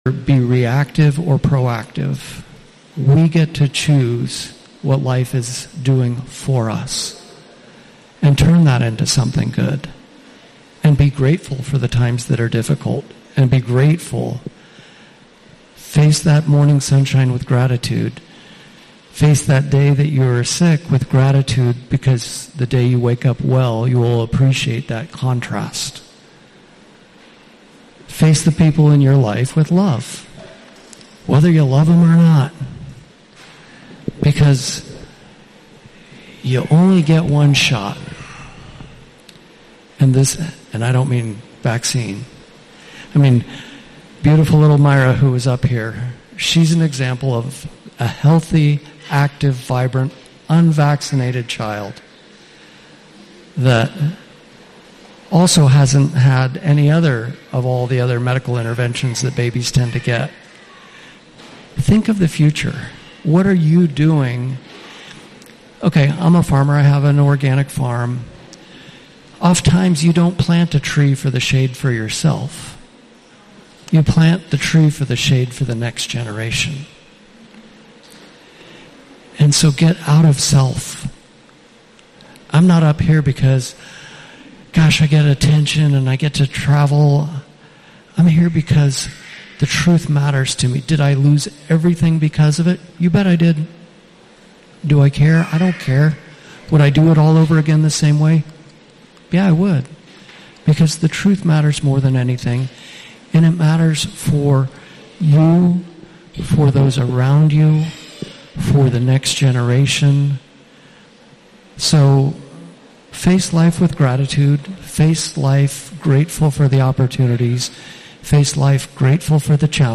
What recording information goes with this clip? Q and A Part Three from Keep Vaccinations Optional Hawaii Island Tour January 2026